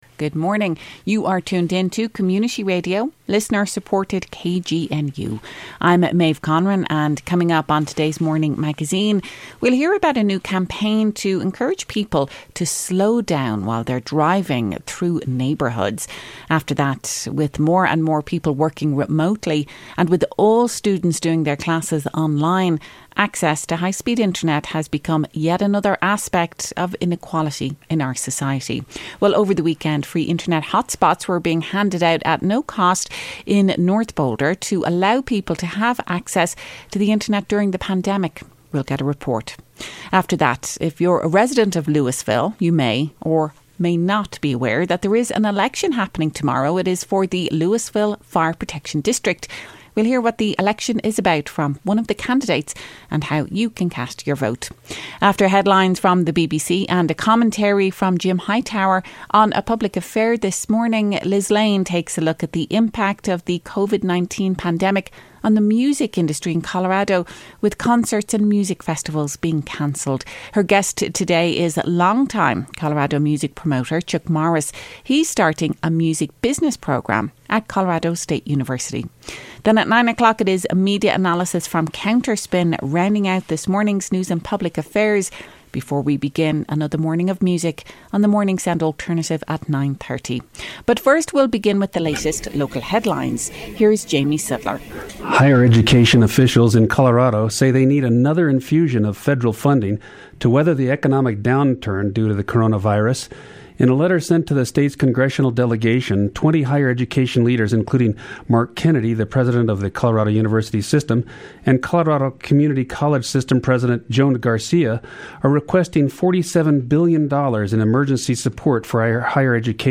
Then KGNU asked about the importance of high-speed internet access at a recent mobile hotspot give away and how that access relates to social equity.